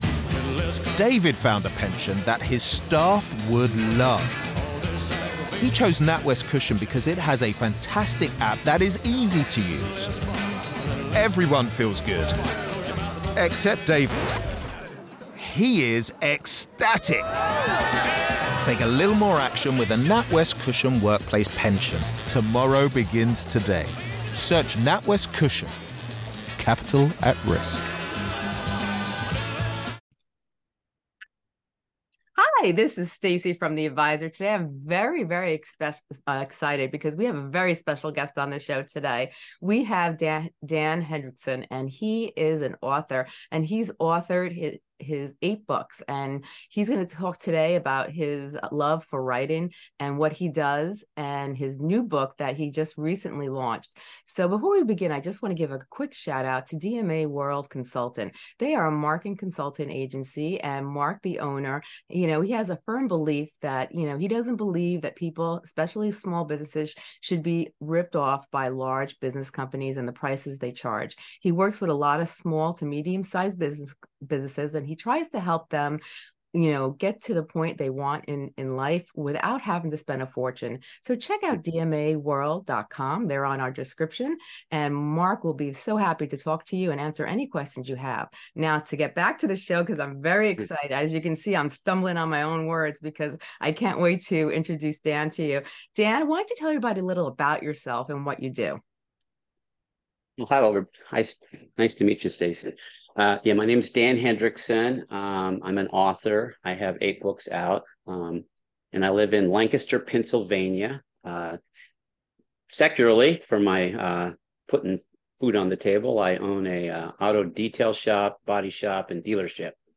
Explore the significance of the astronomical configurations and follow their journey to petition for safe passage to Judea. Discover the captivating story filled with intrigue, culture, and the search for the Messiah. Don't miss this enlightening conversation